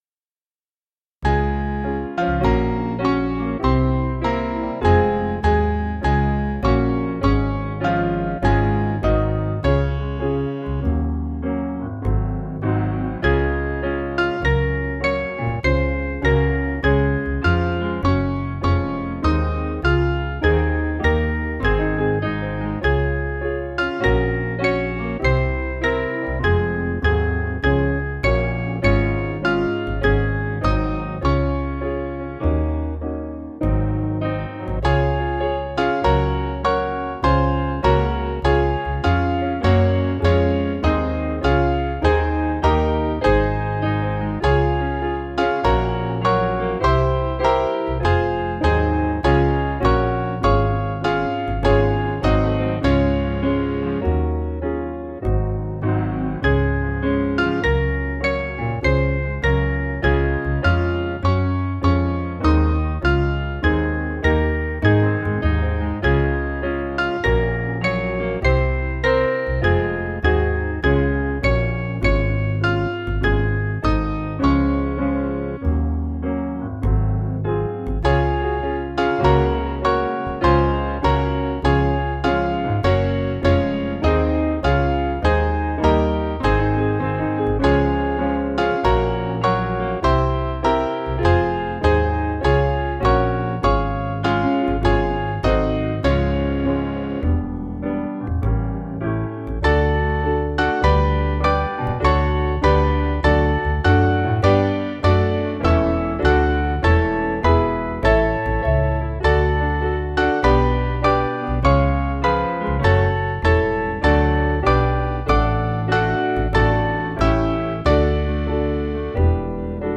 Mainly Piano